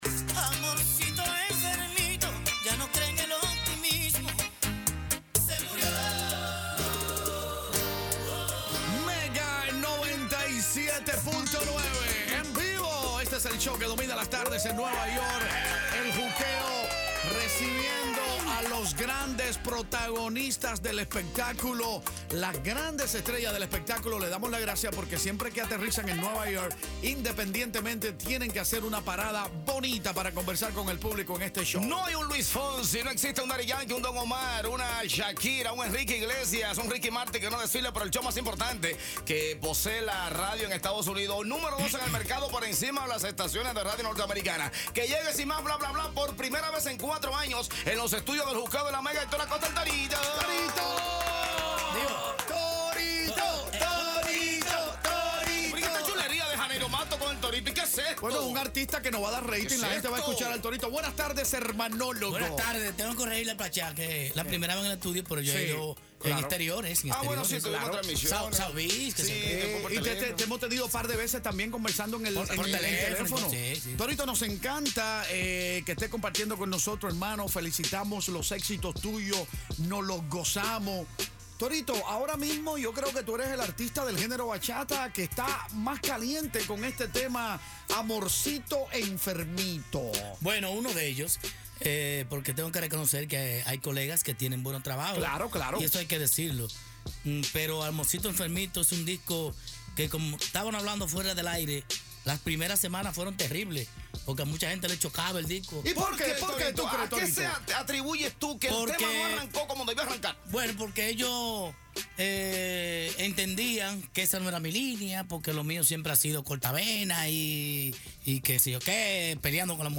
ENTREVISTA CON HECTOR ACOSTA EL TORITO PARTE 1 • La Mega 97.9
ENTREVISTA-CON-HECTOR-ACOSTA-EL-TORITO-PARTE-1.mp3